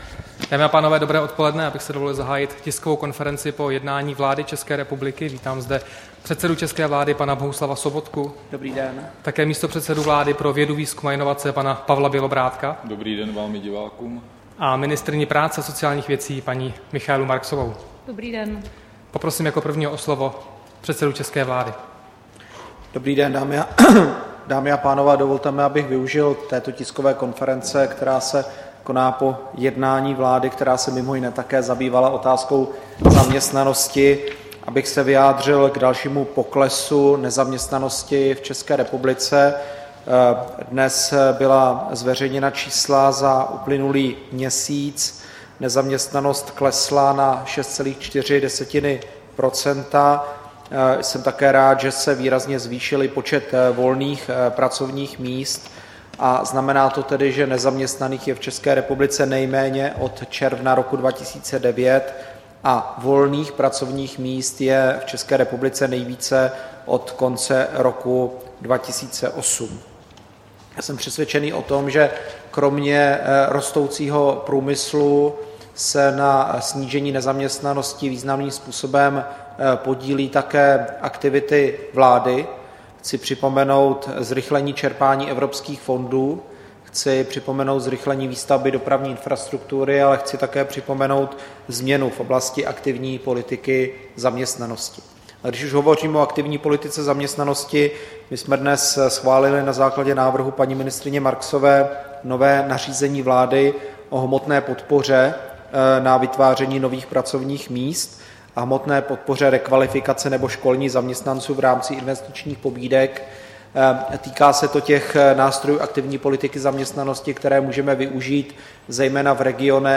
Tisková konference po jednání vlády, 8. června 2015